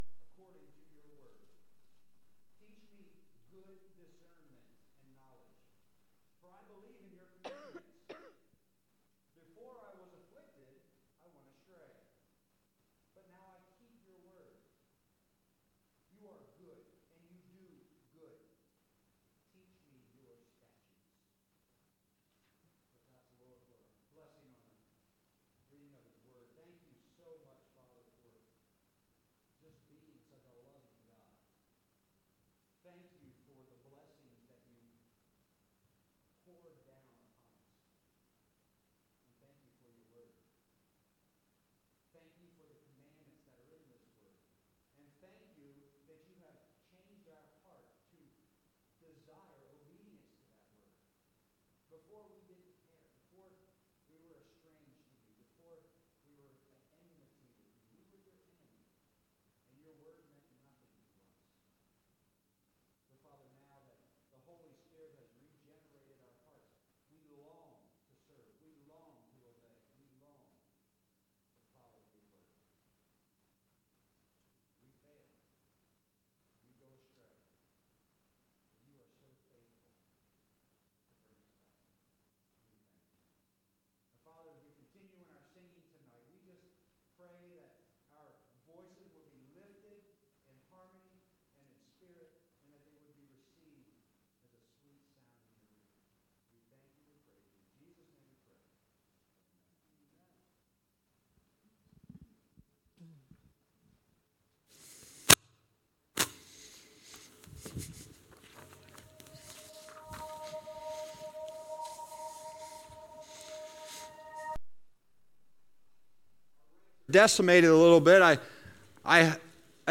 Sunday Evening Worship December 28, 2025.
The Pulpit Ministry of New Covenant Church, Lewes